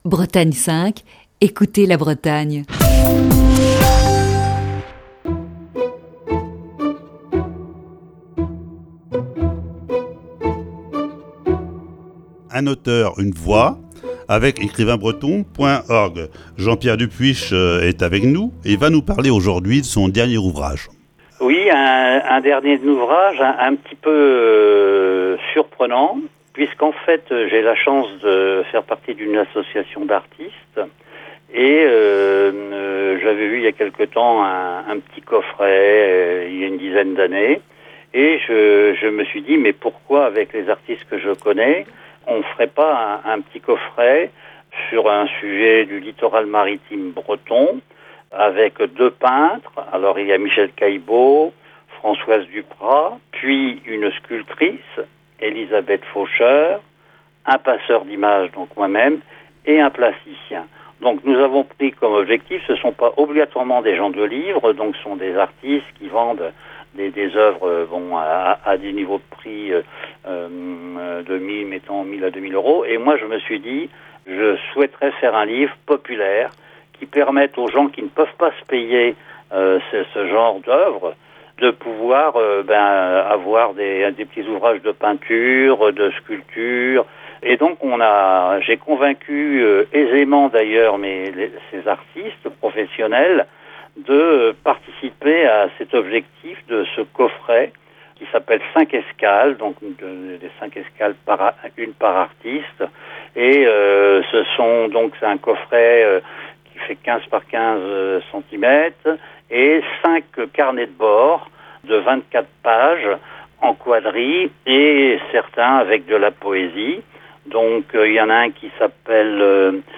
Voici ce vendredi la cinquième et dernière partie de cet entretien.